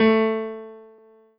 piano-ff-37.wav